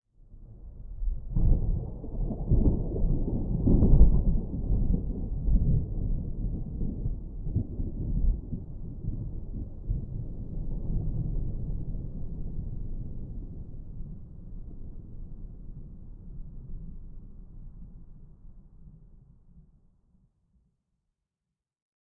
thunderfar_24.ogg